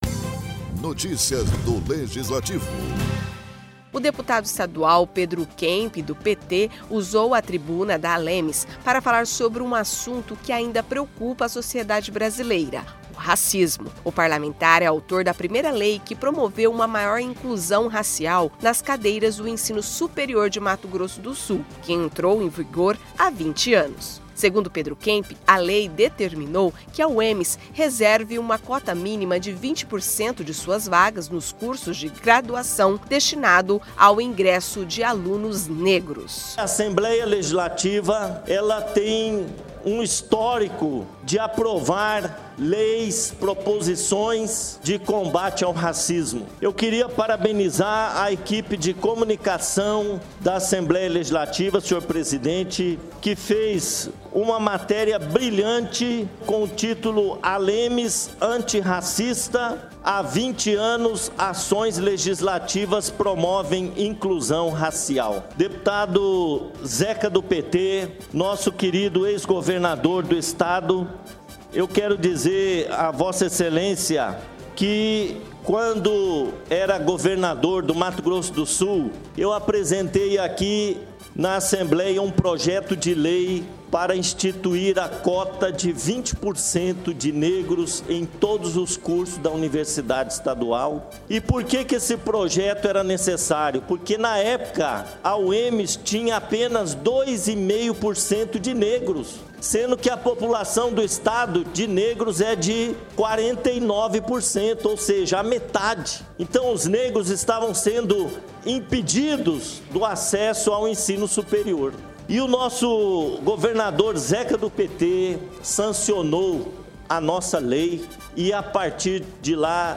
Na tribuna da ALEMS, deputados falam da importância do combate ao racismo
O deputado estadual Pedro Kemp (PT), usou a tribuna da ALEMS, para falar sobre um assunto que ainda preocupa a sociedade brasileira o Racismo.